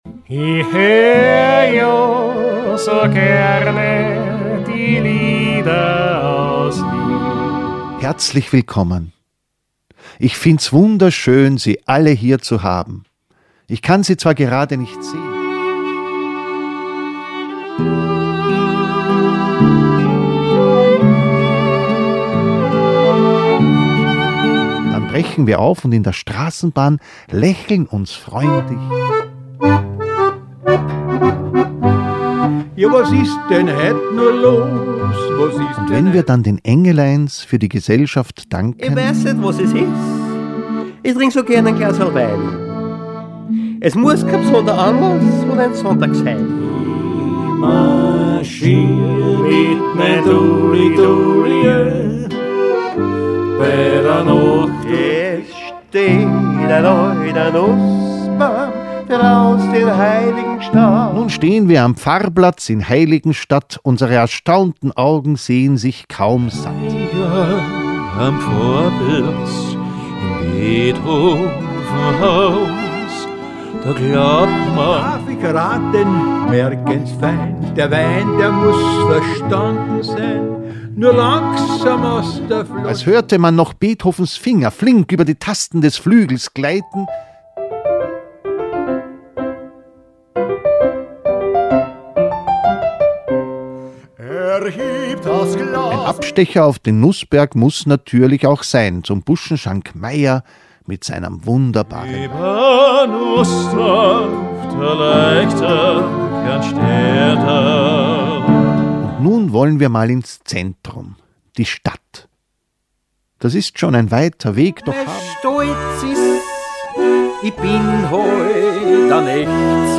Hier hören Sie ein kurzes Stück von jedem Titel